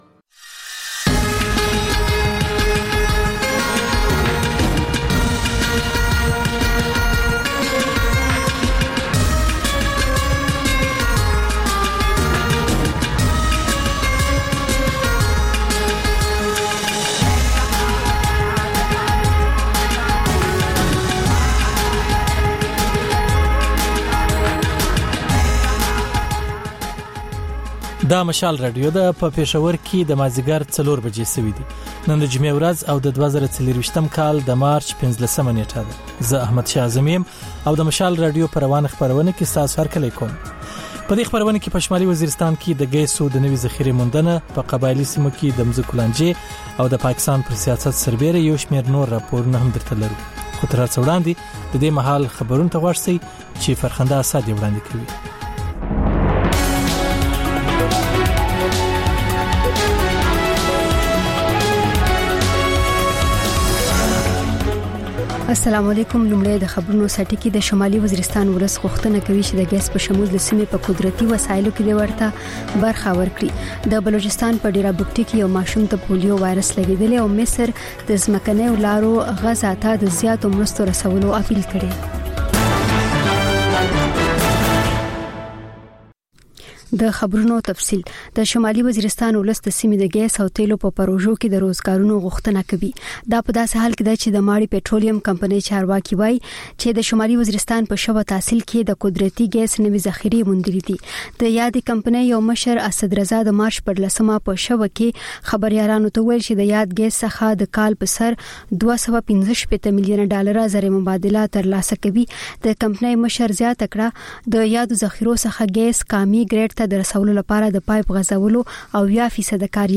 د مشال راډیو مازیګرنۍ خپرونه. د خپرونې پیل له خبرونو کېږي، ورسره اوونیزه خپرونه/خپرونې هم خپرېږي.